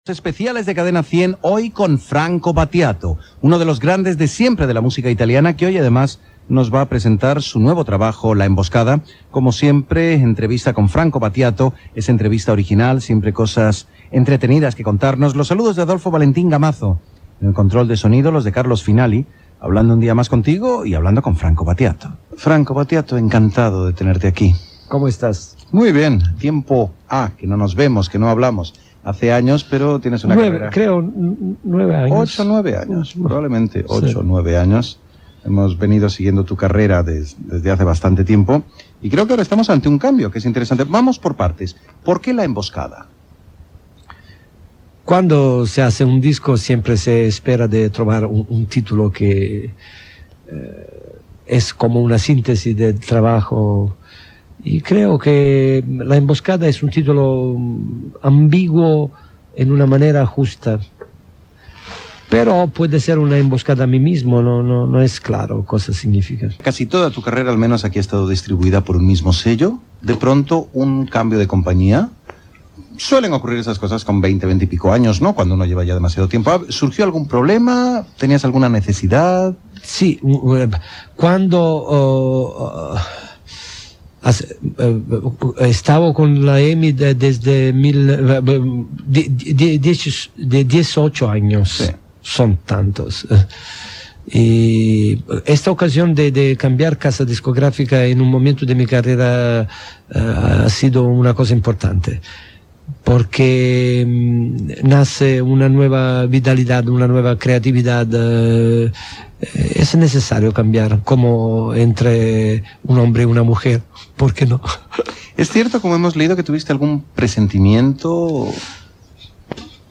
Presentació del programa i entrevista al cantant Franco Battiato que presenta el disc "La emboscada"
Musical